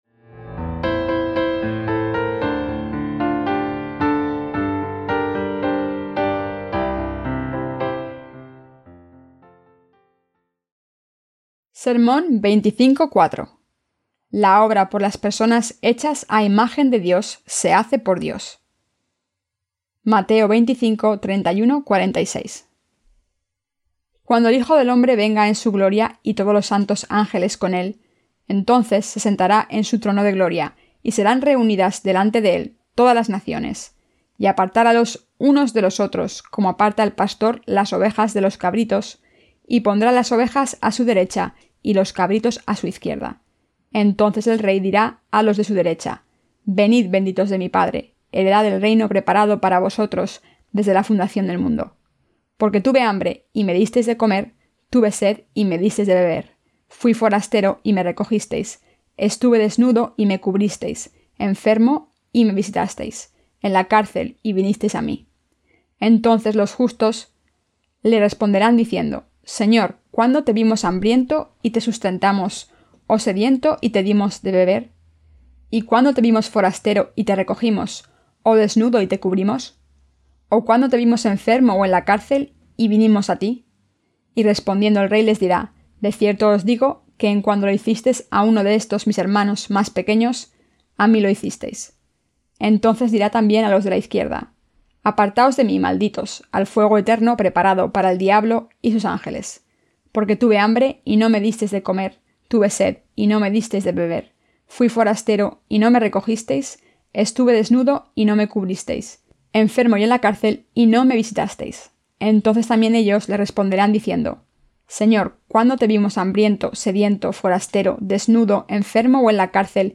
SERMONES SOBRE EL EVANGELIO DE MATEO (VI)-¿A QUIÉN SE LE PRESENTA LA MEJOR VIDA?